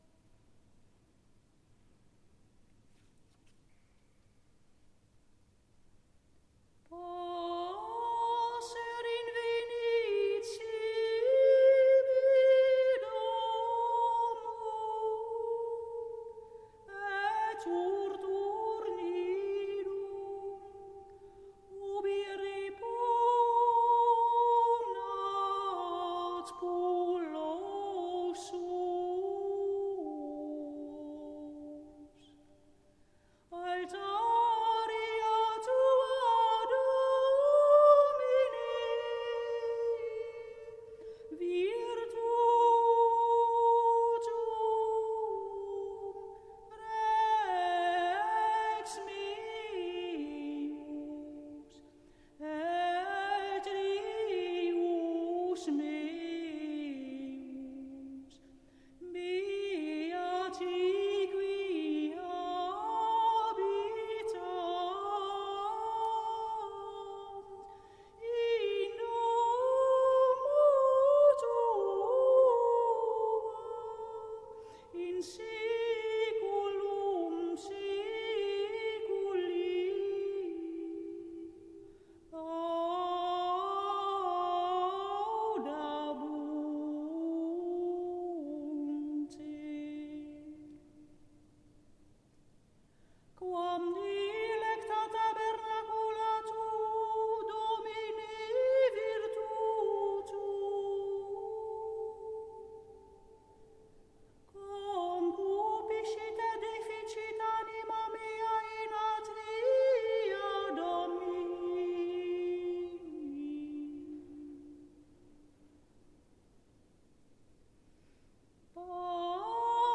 Passer invenit (Ps. 83, 4-5), communio  WMP   RealPlayer